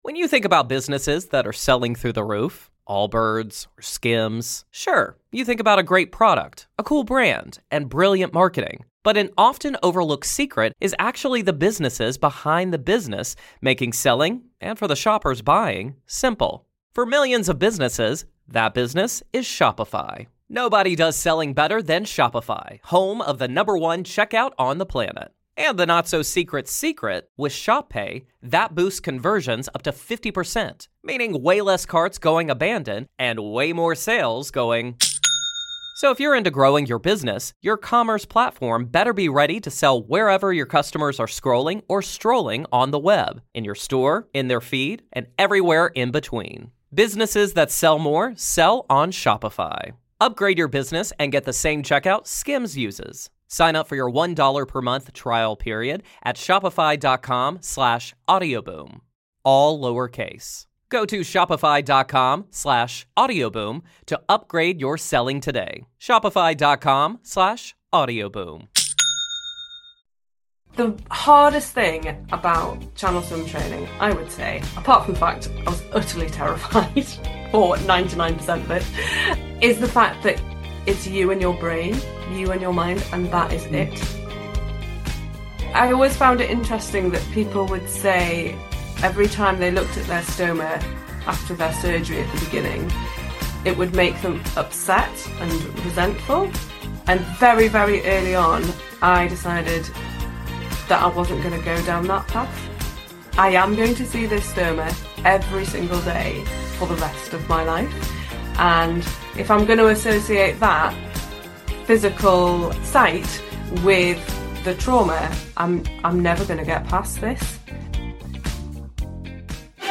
Surgeons, nurses, specialists in stoma & cancer care, IBS or Crohn's disease sufferers, people just like her, living with a stoma. She is here to debunk myths, clear up misconceptions and help you approach the subject that so many people are afraid to talk about, in a positive manner.